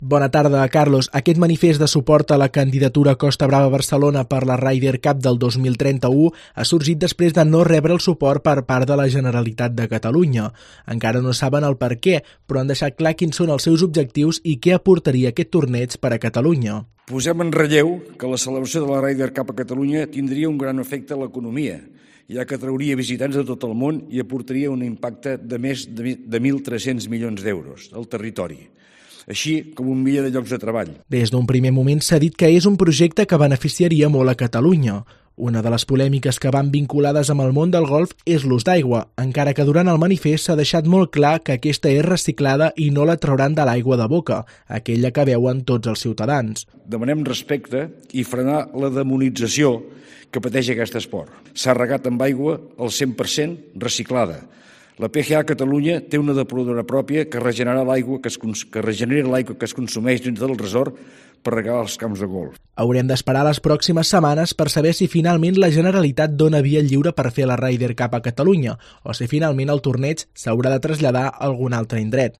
Crónica del peligro de la Ryder Cup 2031 si la Generalitat no aprueba el proyecto